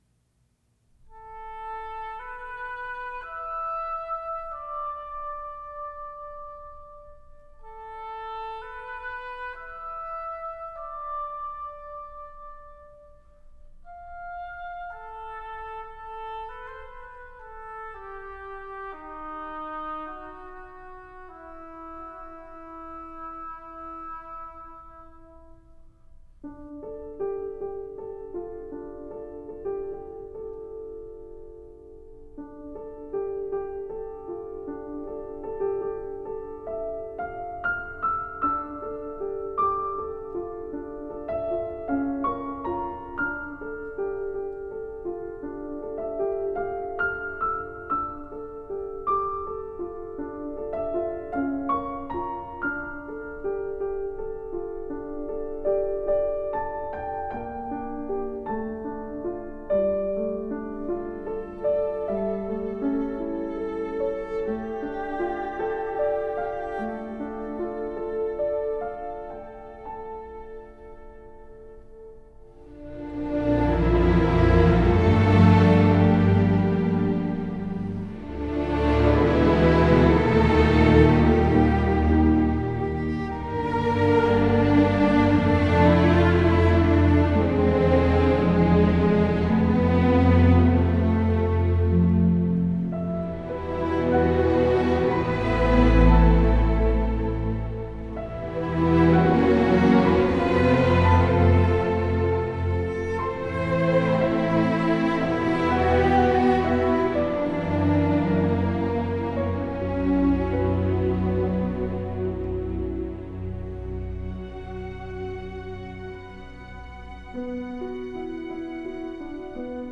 版本：Score